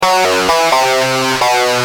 Lead_b2.wav